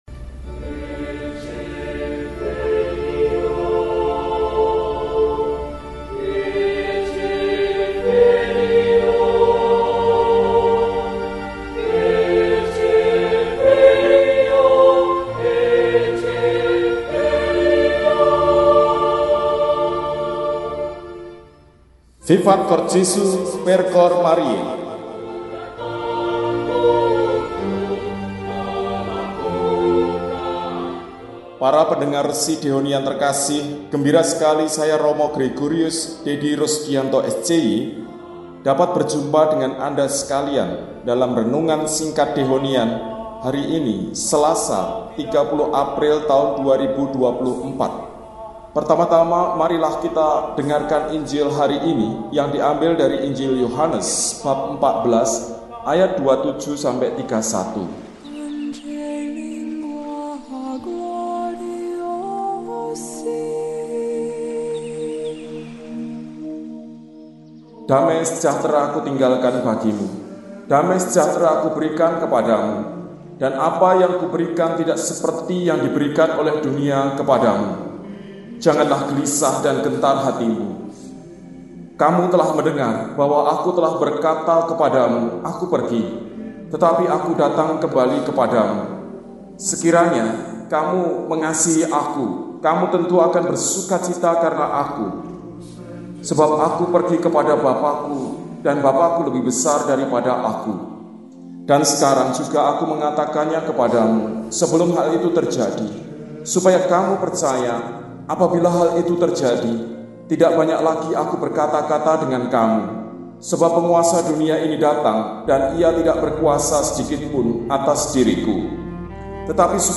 Selasa, 30 April 2024 – Hari Biasa Pekan V Paskah – RESI (Renungan Singkat) DEHONIAN